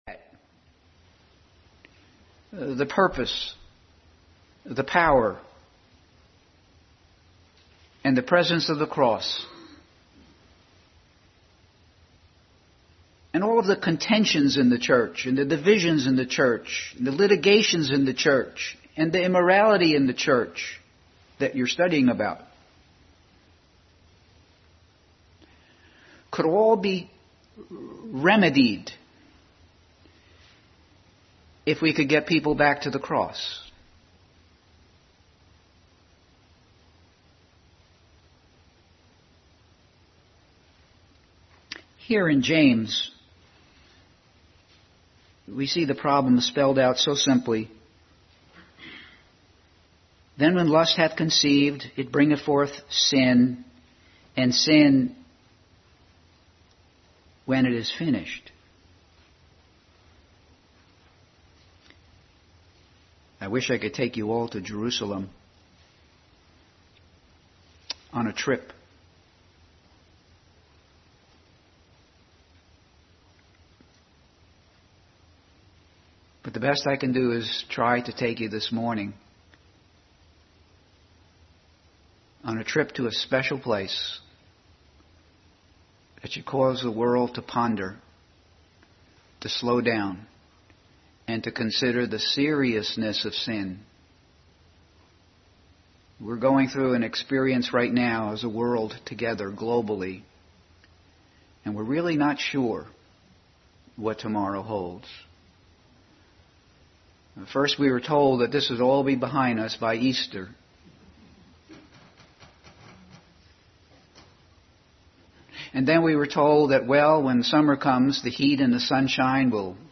Bible Text: Matthew 27:33-50, 1 Corinthians 1:17-18, James 1:14-15 | Adult Sunday School class.